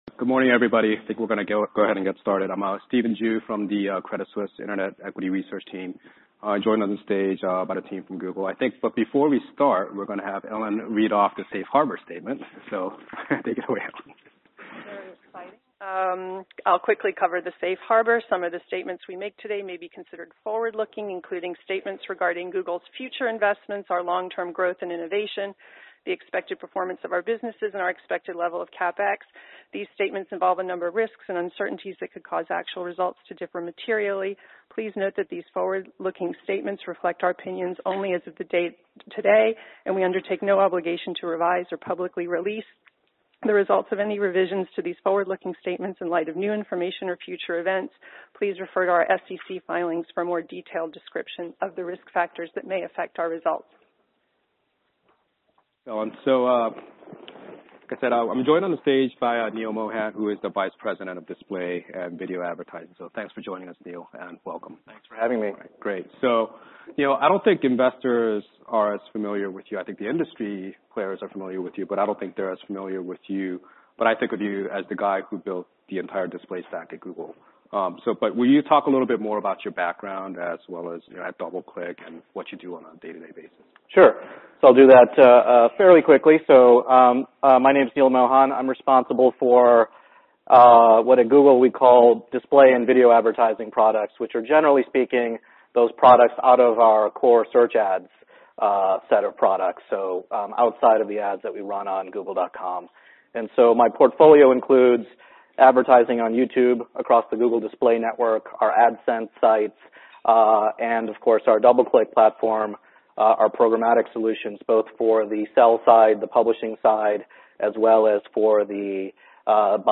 Google’s Neal Mohan, Vice President of Display and Video Advertising, presents at Credit Suisse Technology Conference on December 2, 2014 11:15 AM ET.